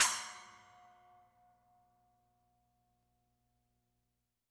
R_B Splash B 01 - Close.wav